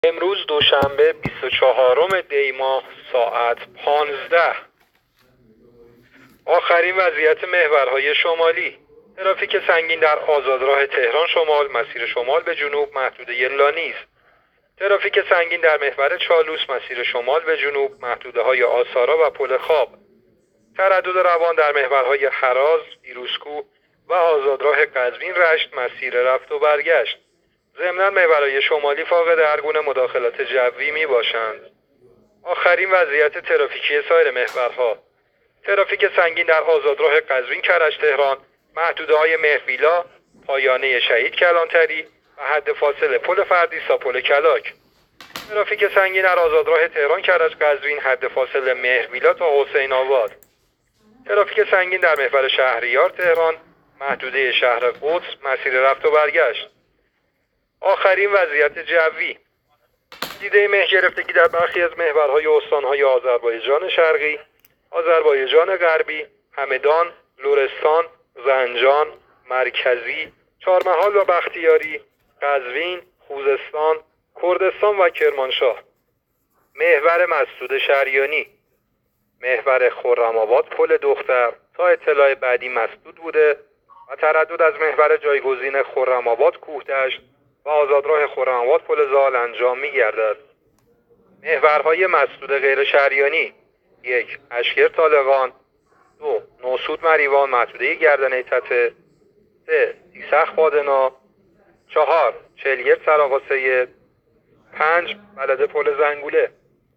گزارش رادیو اینترنتی از آخرین وضعیت ترافیکی جاده‌ها تا ساعت ۱۵ بیست‌وچهارم دی؛